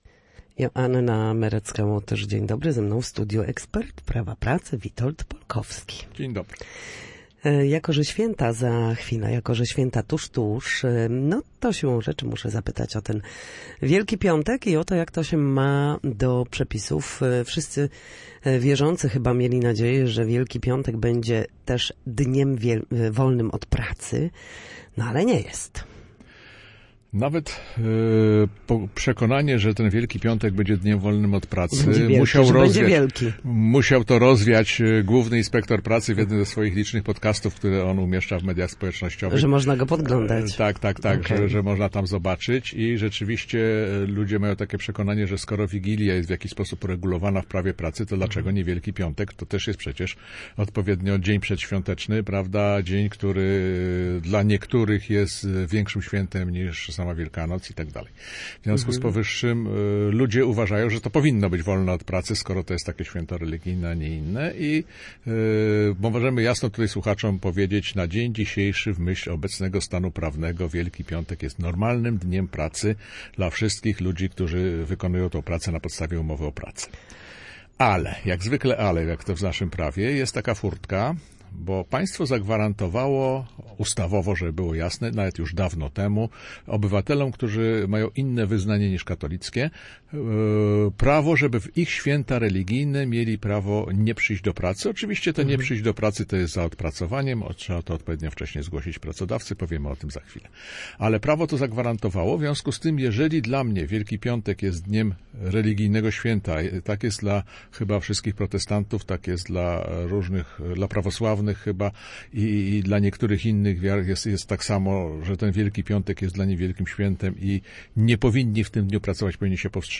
W każdy wtorek po godzinie 13:00 na antenie Studia Słupsk przybliżamy Państwu zagadnienia dotyczące prawa pracy.